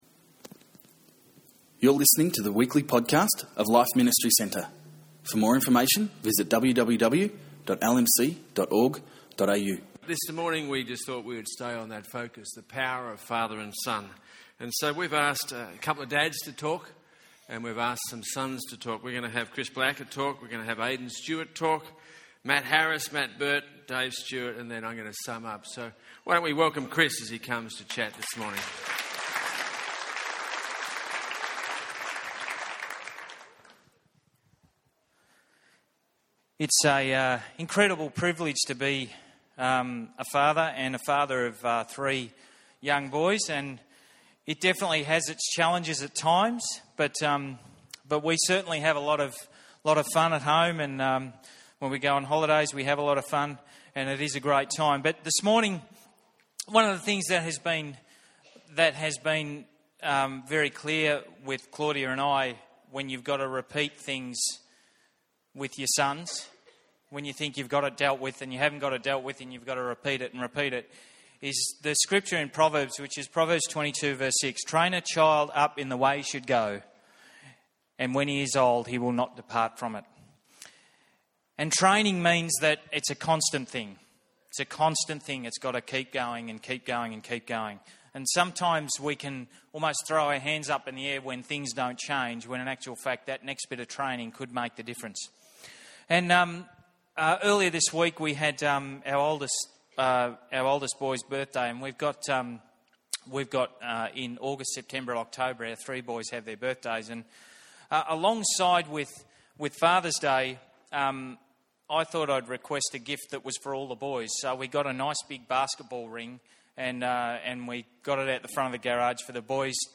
In this special Father's Day Service, we honour Dads. A number of LMC Fathers and Sons share principles that illustrate 'The Power of Father and Son.'